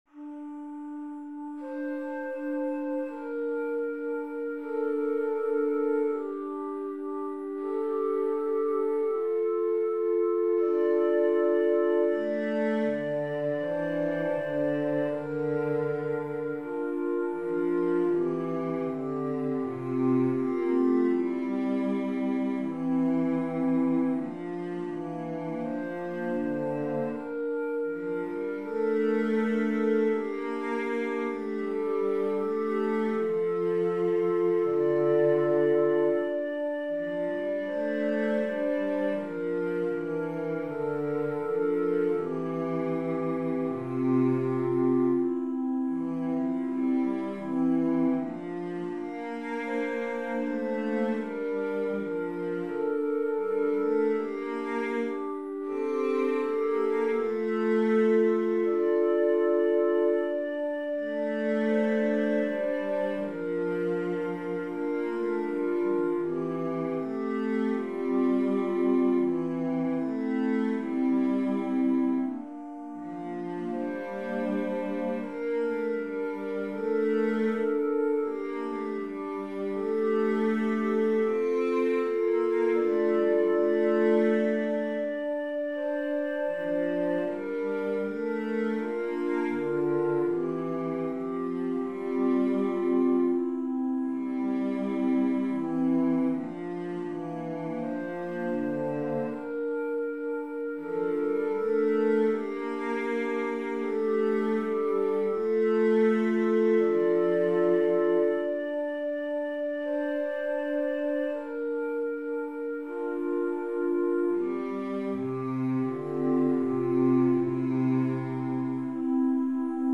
Voicing/Instrumentation: SSAATB